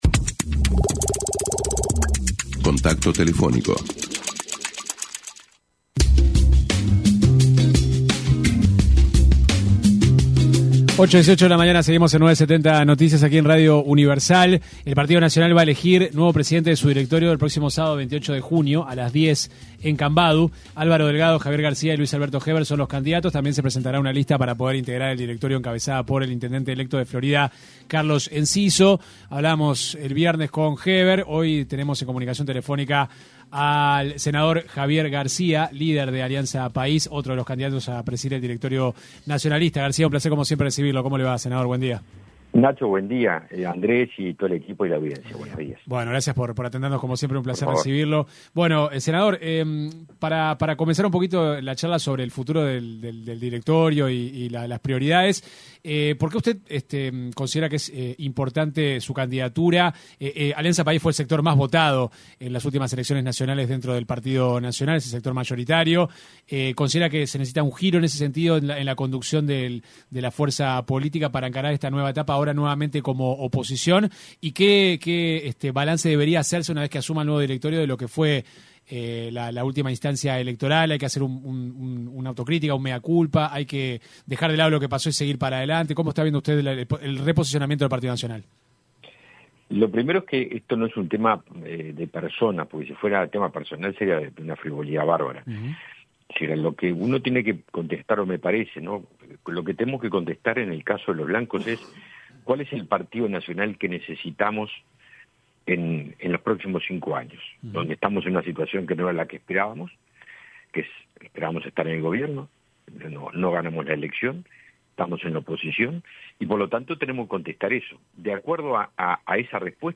Escuche la entrevista completa aquí: El senador de la República y candidato a la presidencia del Honorable Directorio del Partido Nacional, aseguró en diálogo con 970 Noticias, que en caso de ser electo presidente de su fuerza política se tomará licencia sin goce de sueldo en el Senado.